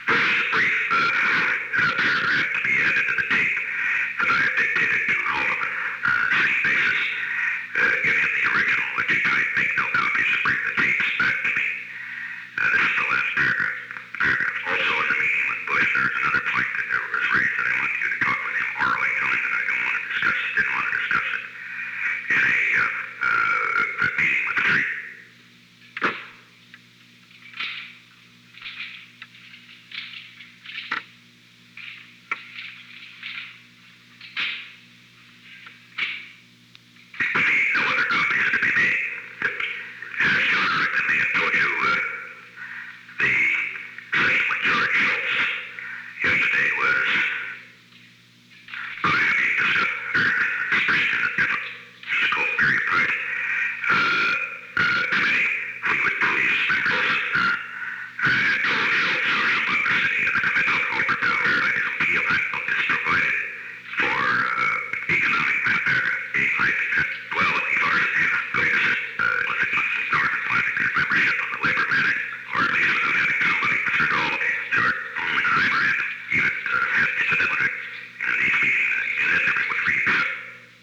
Location: Oval Office
The President played portions of a previously recorded dictabelt tape.